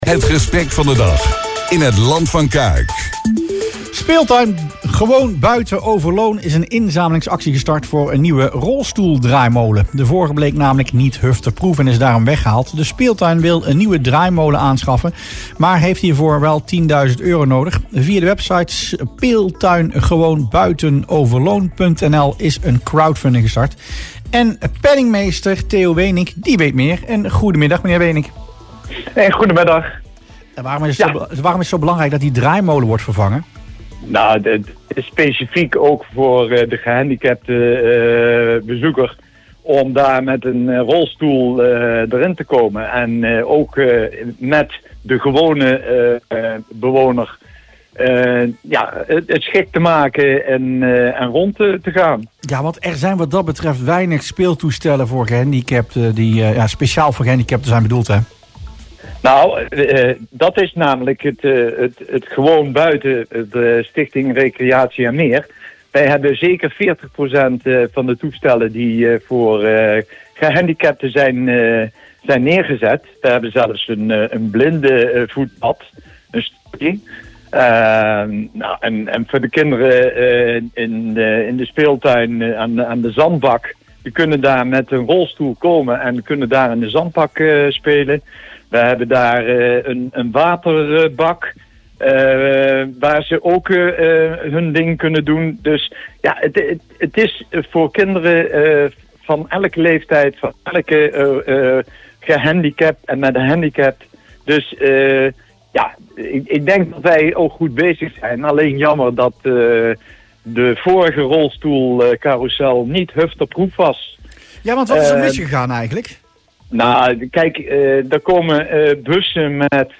in het radioprogramma Rustplaats Lokkant